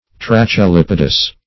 trachelipodous - definition of trachelipodous - synonyms, pronunciation, spelling from Free Dictionary
trachelipodous.mp3